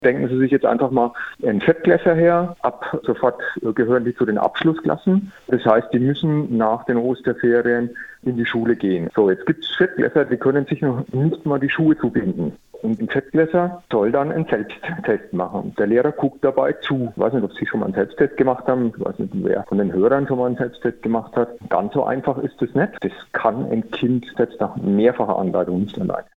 Wir haben Ihn interviewt.